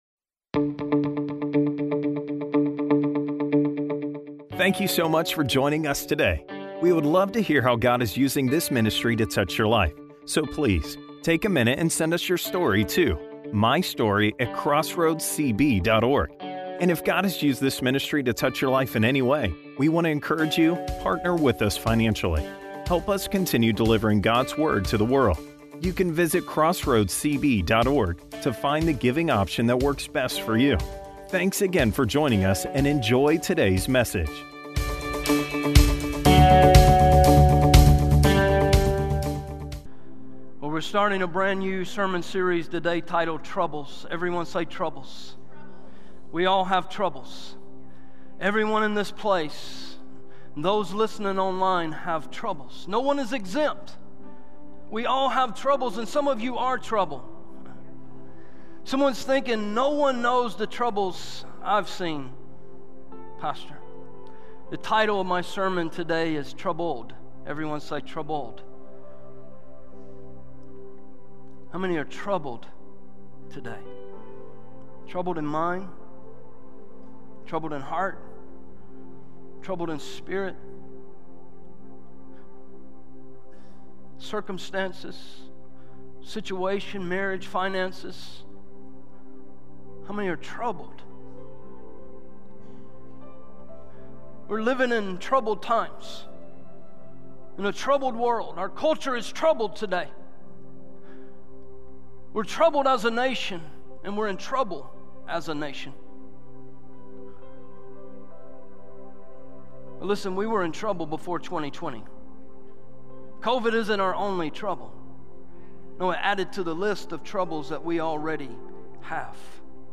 Sermons - Crossroads Church